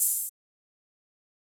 Live For Open Hat.wav